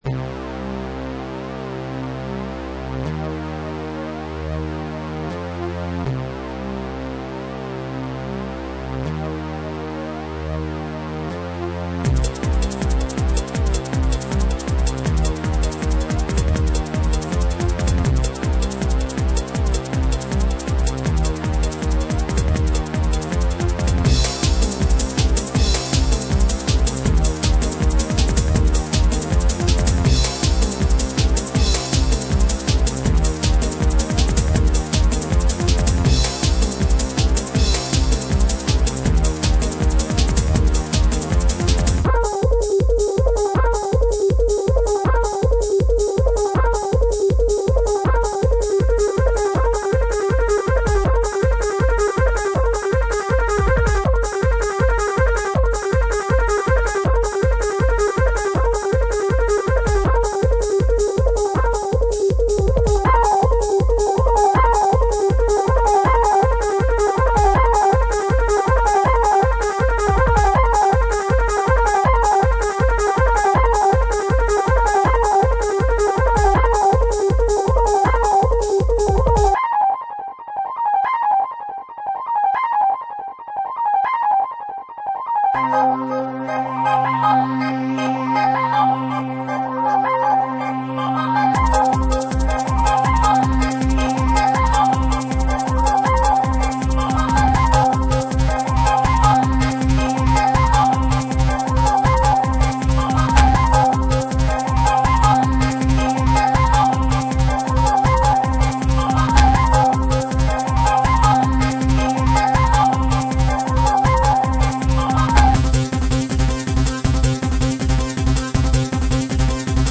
Techno
I think it has a euphoric sound to it. I incorporated lots of volume and reverse mixing to the tracks to generate the effect.